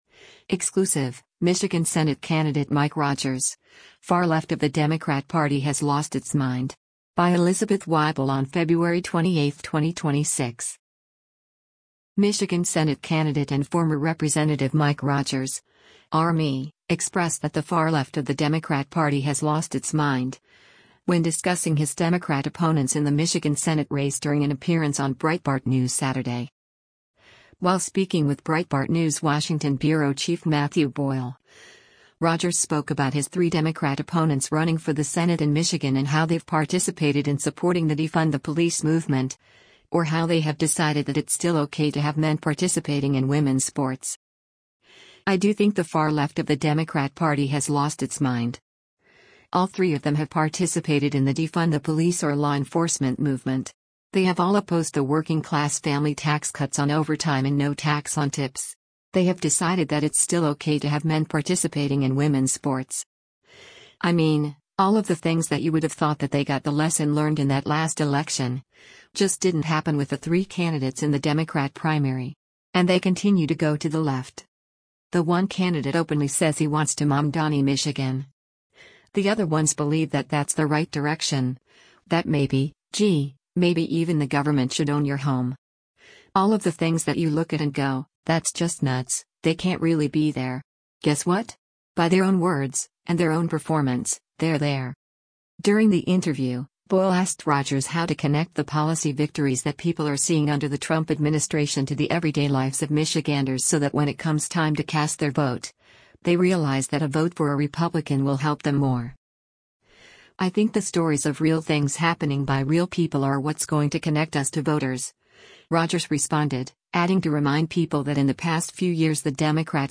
Michigan Senate candidate and former Rep. Mike Rogers (R-MI) expressed that the “far left of the Democrat Party has lost its mind,” when discussing his Democrat opponents in the Michigan Senate race during an appearance on Breitbart News Saturday.